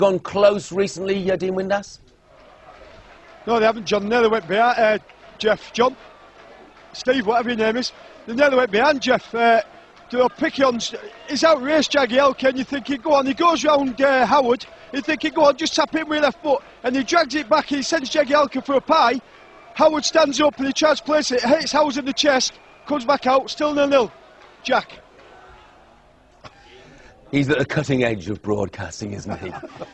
Football commentator
Tags: News blooper news news anchor blooper bloopers news fail news fails broadcaster fail